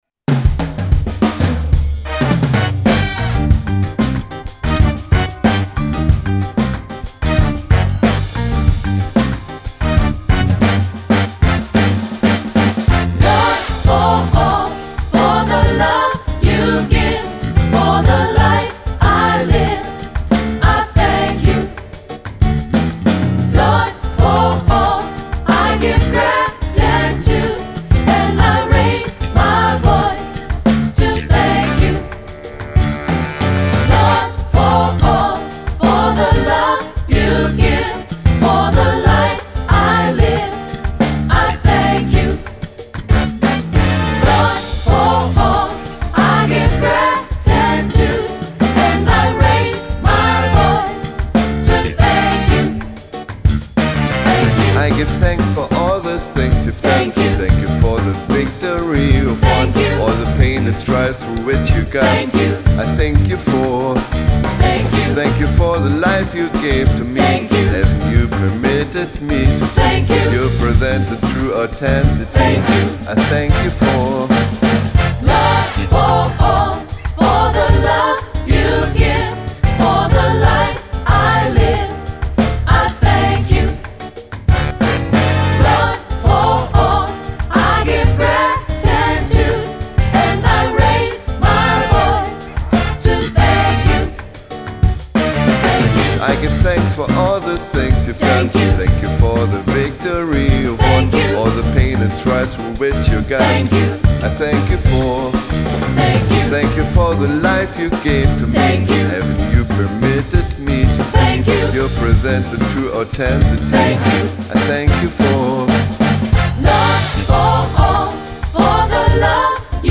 GOSPELNOTEN
Latin-Gospel-Songs
• SAB (SSA), Solo + Piano